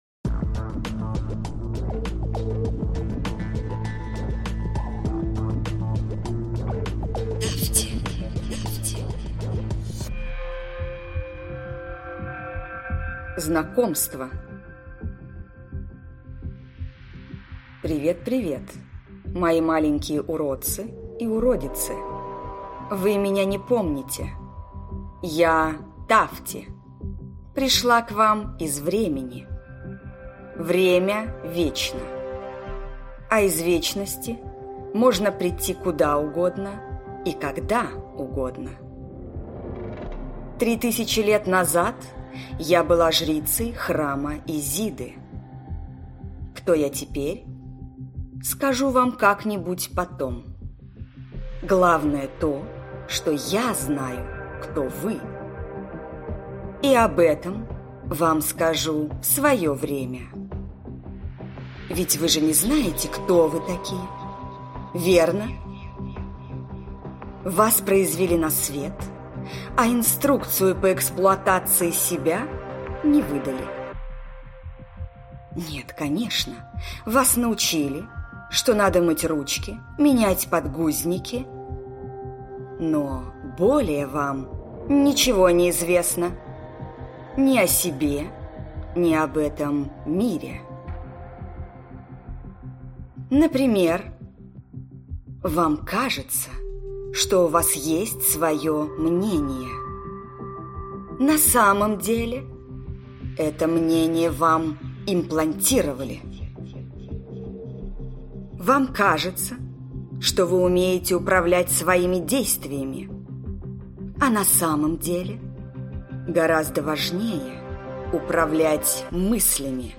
Аудиокнига Тафти жрица. Гуляние живьем в кинокартине + Тафти жрица 2. Управление событиями | Библиотека аудиокниг